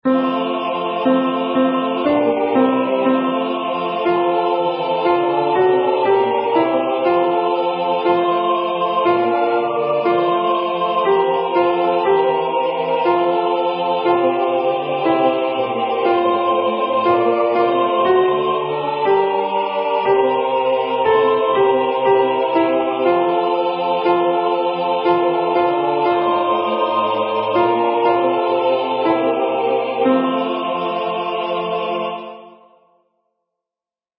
MP3 Practice Files: Soprano:   Alto:
Number of voices: 4vv   Voicing: SATB
Genre: SacredAnthem
BeautifulSaviourAltoP.mp3